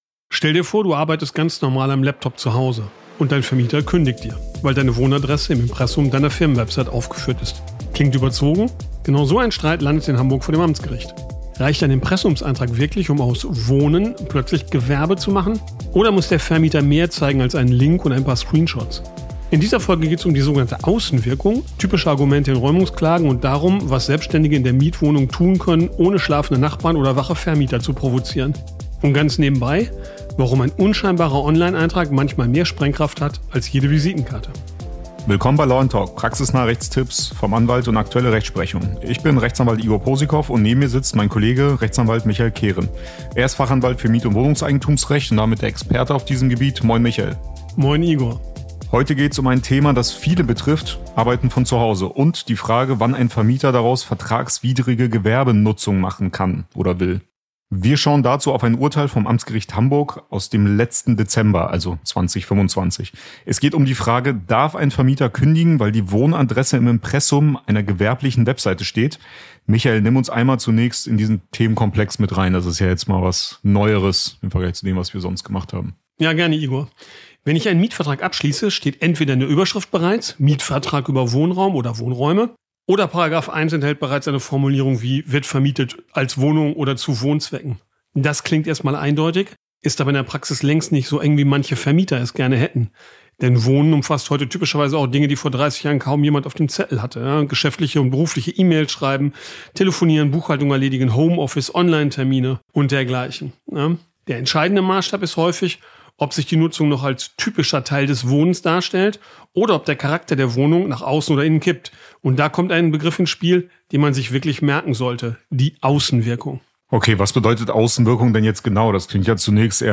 (Fachanwalt für Mietrecht) ein brandaktuelles Urteil des AG Hamburg